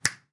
描述：我打着响指，用Rode NT1A录制
Tag: 手指 点击 按扣 流行音乐